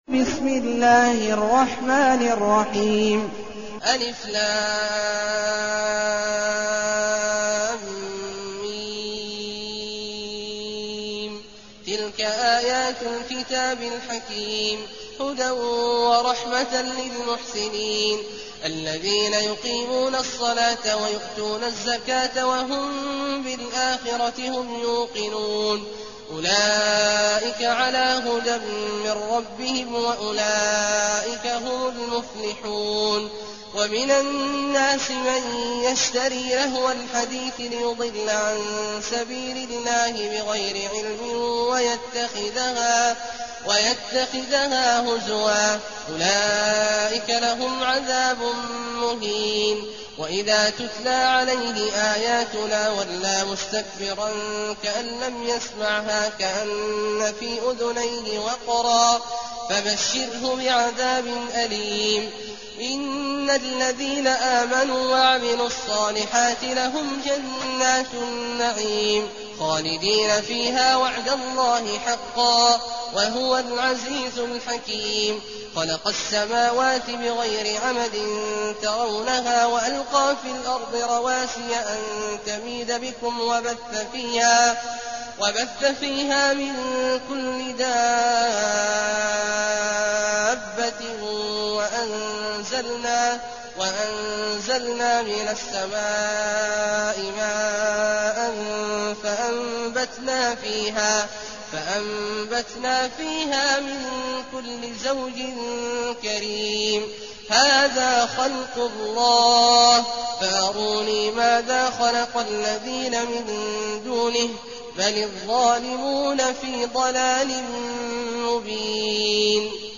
المكان: المسجد النبوي الشيخ: فضيلة الشيخ عبدالله الجهني فضيلة الشيخ عبدالله الجهني لقمان